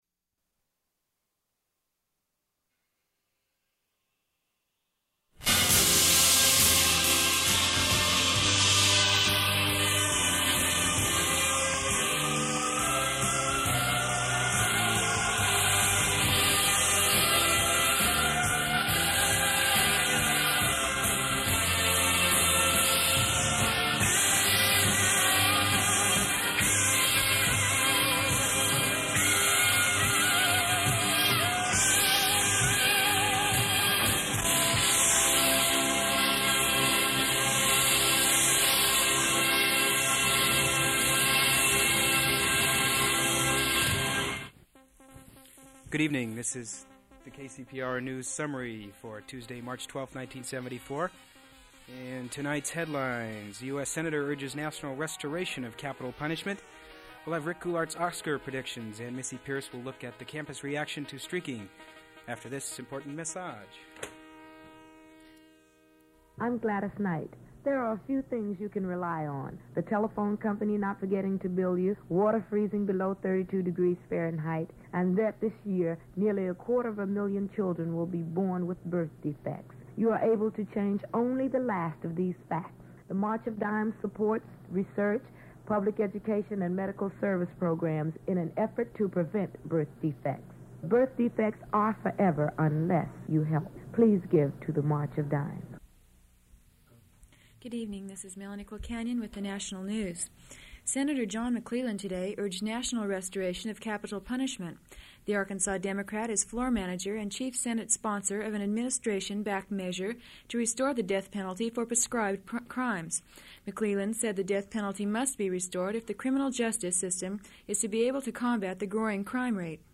[00:00:05] Music begins
March of Dimes PSA
[00:33:25] Recording ends mid-speech
Form of original Open reel audiotape